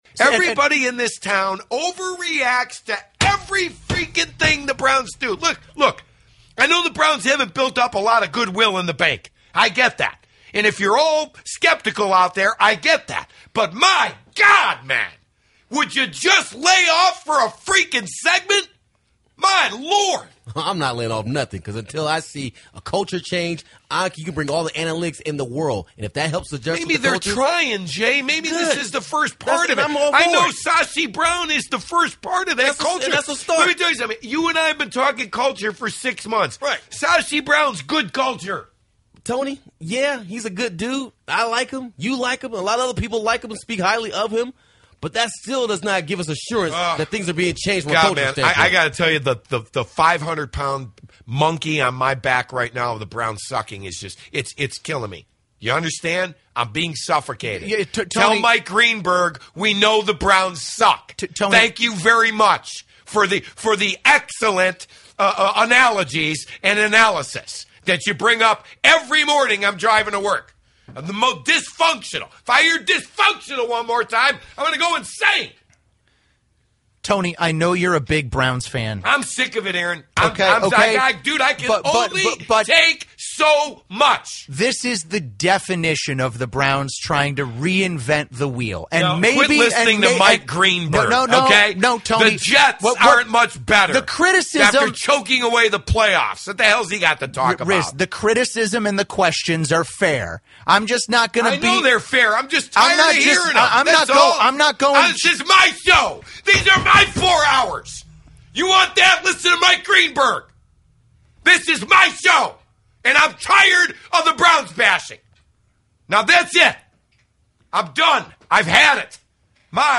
How about a nice little rant to get your morning started?
Sounds like he’s on the verge of a breakdown and we can only pray it happens live on the air.